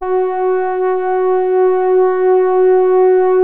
MOOG HARD#F3.wav